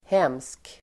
Uttal: [hem:sk]